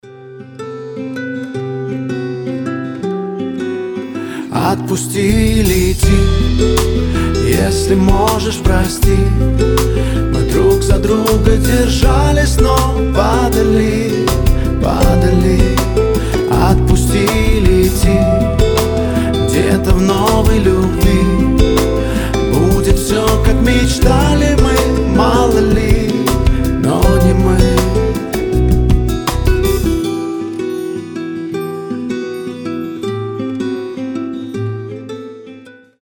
• Качество: 320, Stereo
гитара
мужской голос
грустные
спокойные